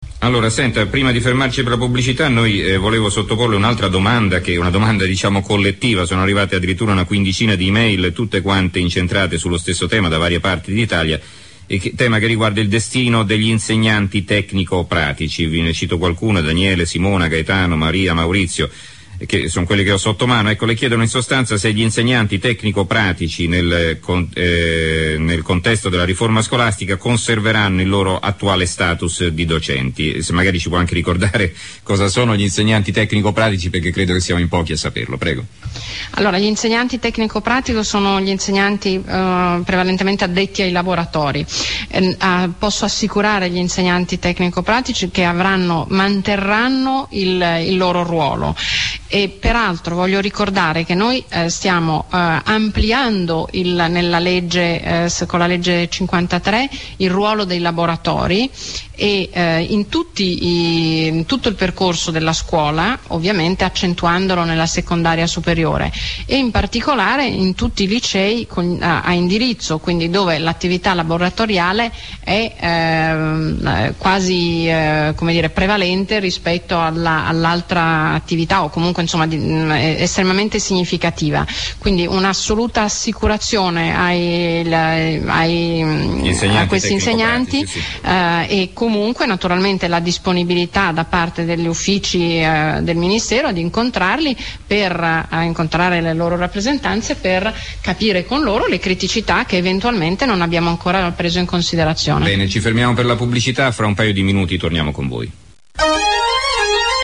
Intervento audio della Moratti sugli ITP